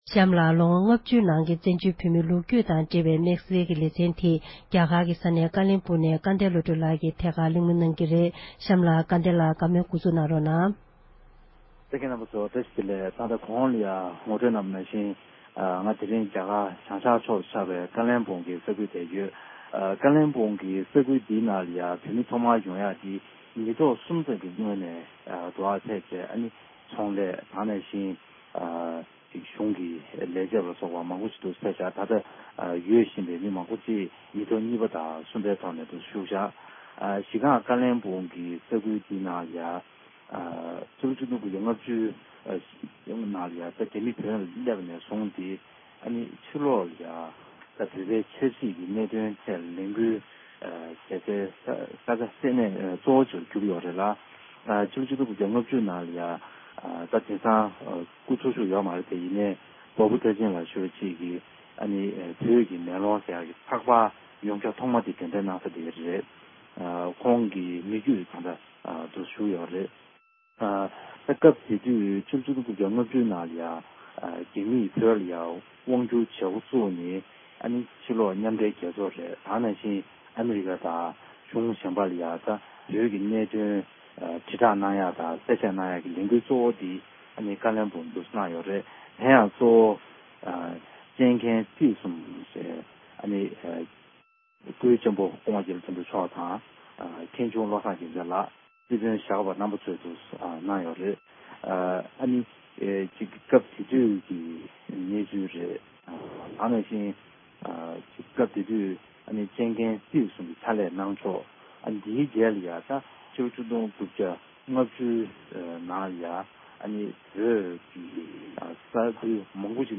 སྐུའི་གཅེན་པོ་བཀའ་ཟུར་རྒྱ་ལོ་དོན་གྲུབ་མཆོག་གི་ལྷན་གླེང་བ།